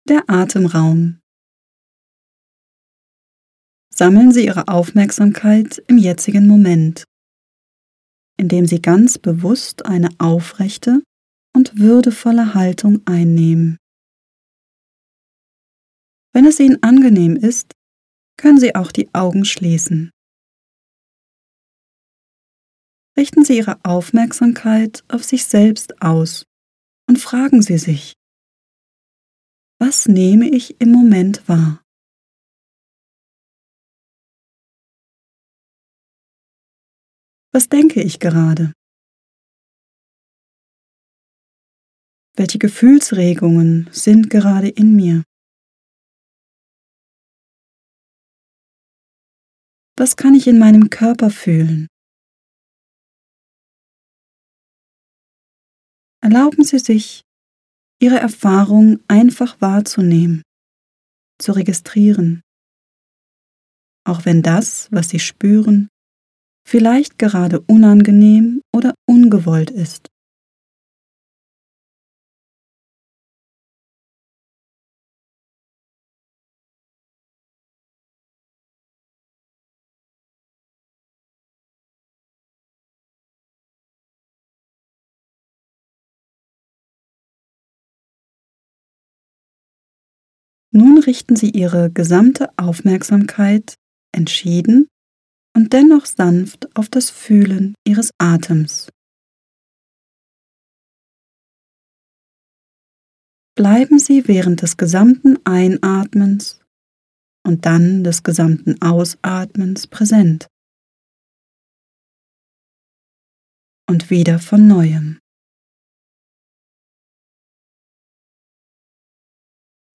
Atemraum-Meditation